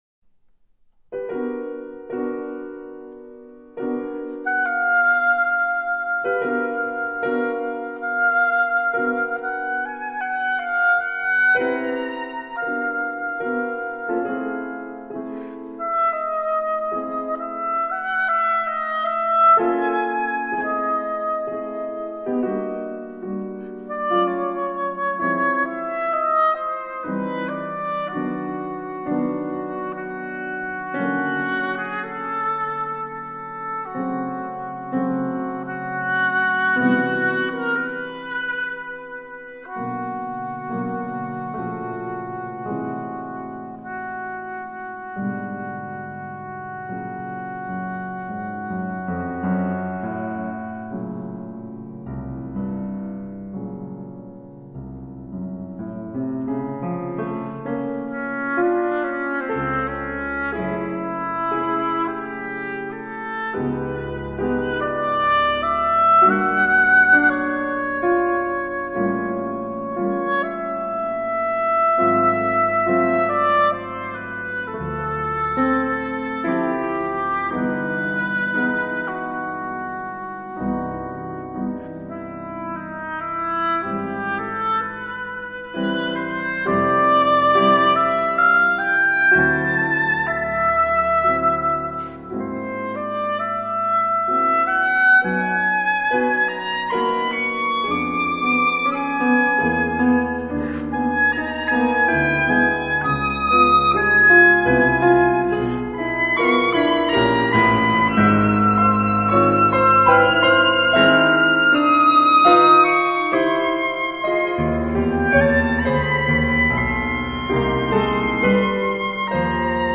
晚安曲分享的是張雙簧管與鋼琴，
心裡一跳，這Oboe好動人，質感又美。
這兩張是在同一段時間，於於德國斯圖加特錄音的。